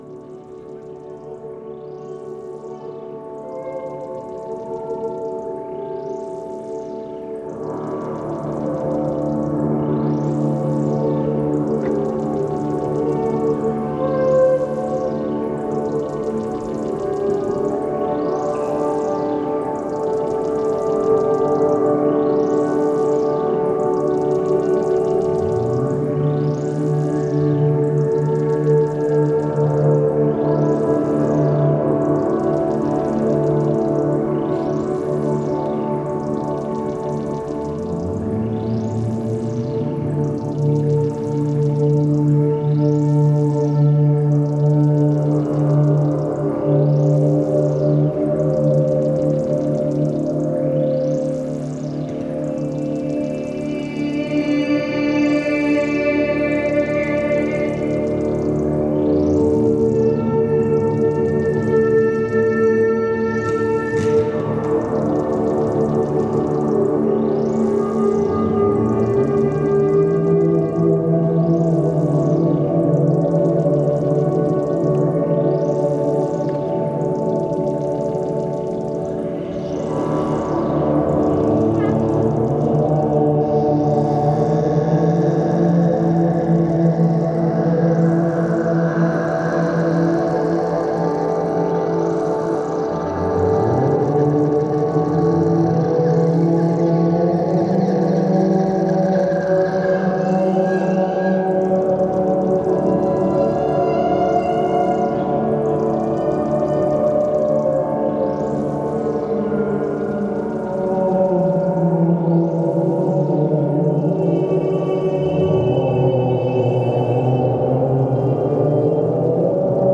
終始不穏な音ですがどこか神聖な空気も宿っており、密教的なドローンを聴いているような妙に安らげる音でもありますね！